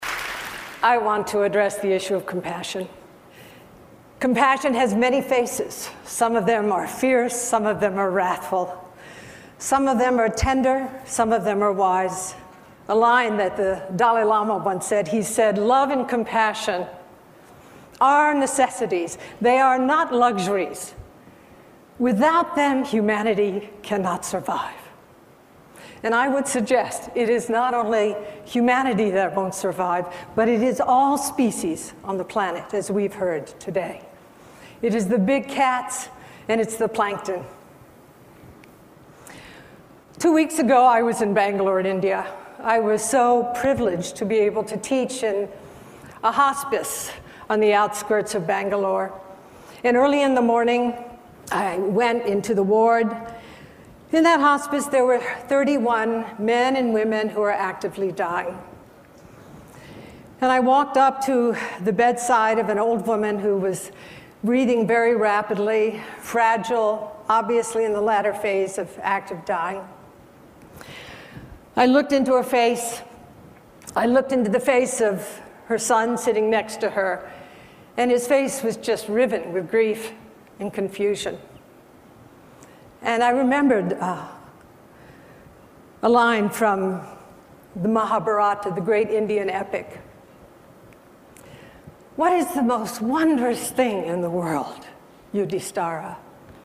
TED演讲:慈悲心与感同身受的真正涵义(1) 听力文件下载—在线英语听力室